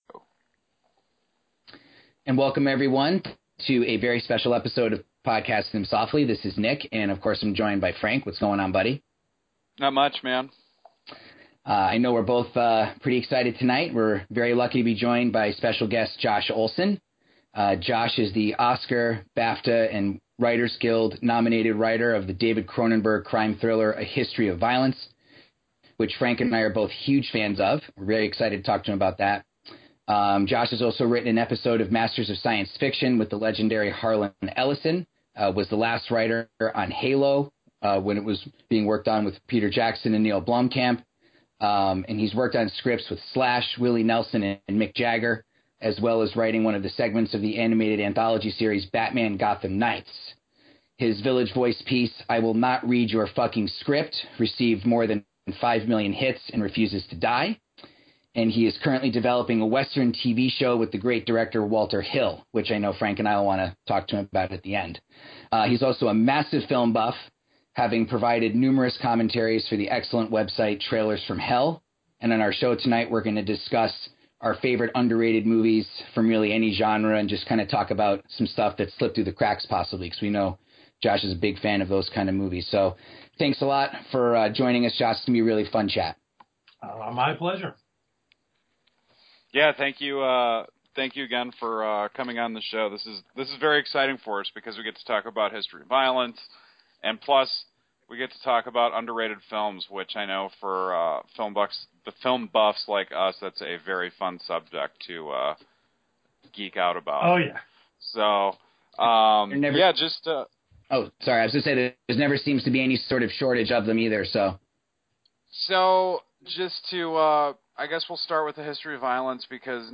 Podcasting Them Softly is extremely excited to present a discussion with special guest Josh Olson.